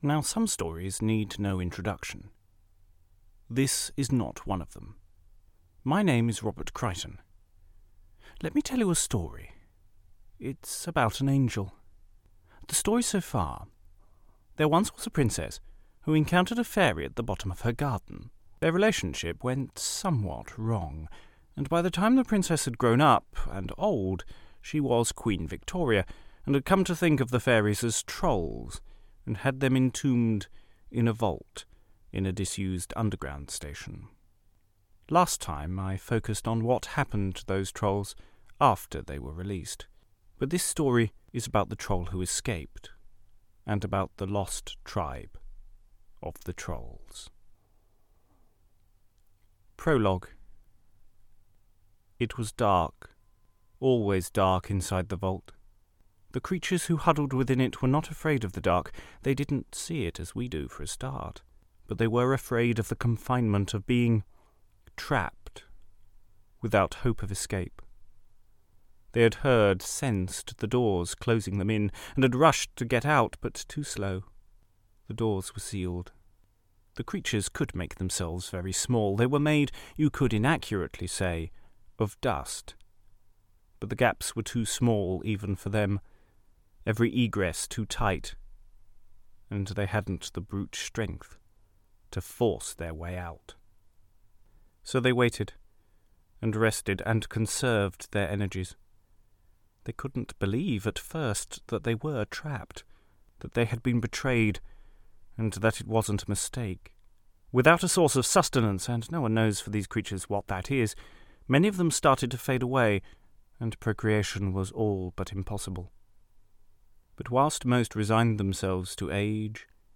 The first part of the story of Lost Tribe of the Trolls, written and performed